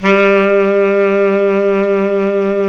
SAX B.SAX 08.wav